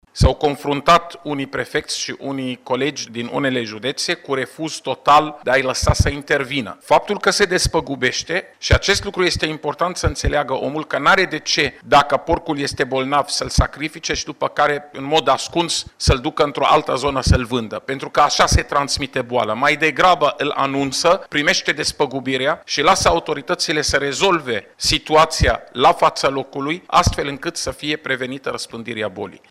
Şeful Departamentului pentru Situaţii de Urgenţă, Raed Arafat: